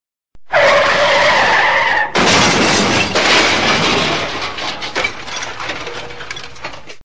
Car Crash Sound
Car Crash